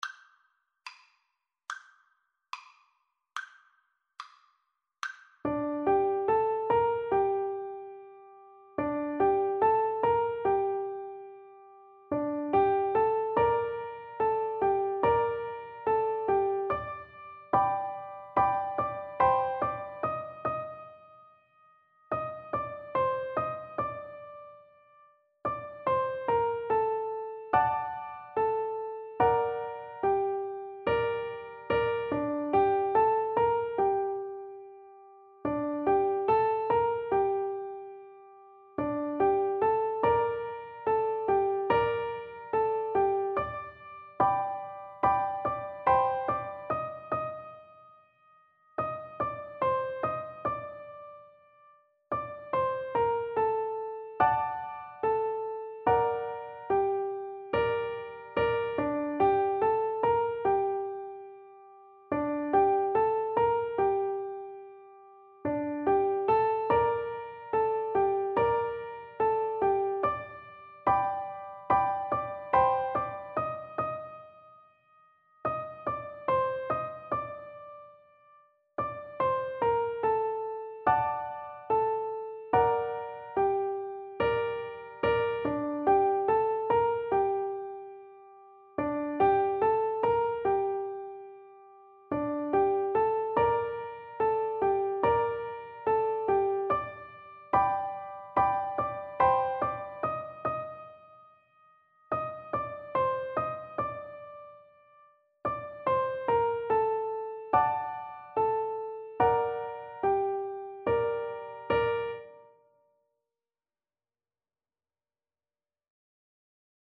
Free Sheet music for Piano Four Hands (Piano Duet)
Andante = c. 72
2/4 (View more 2/4 Music)